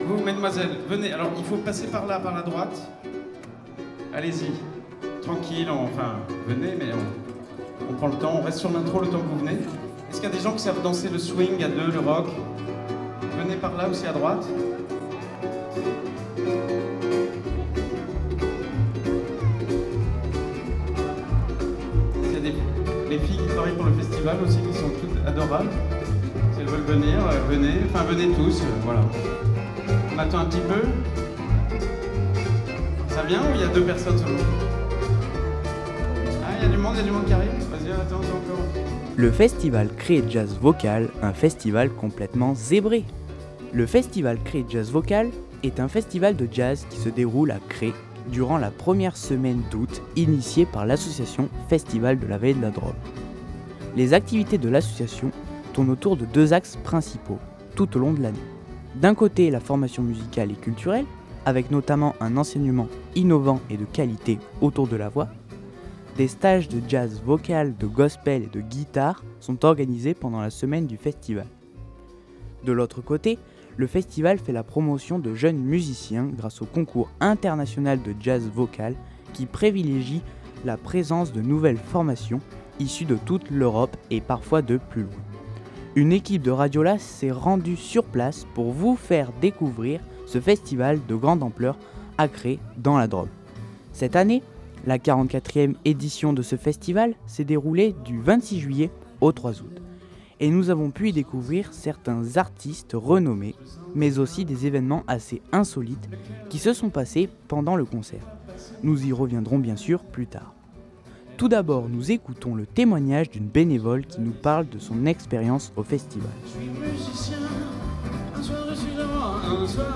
19 novembre 2019 10:47 | Interview, reportage